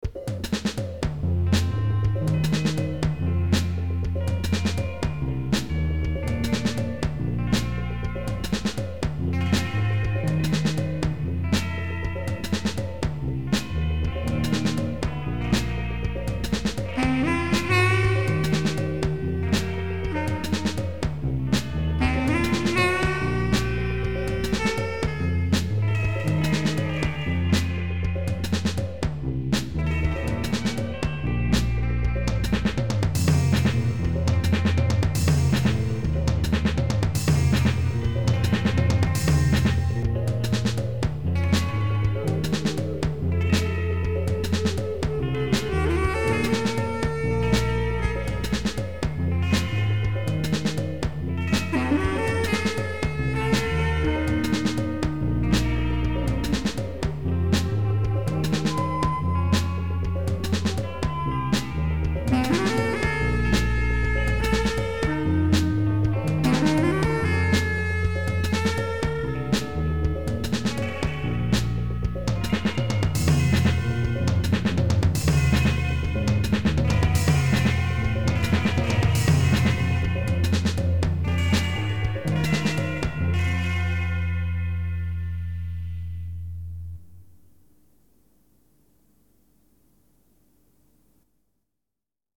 guitar, bass
sax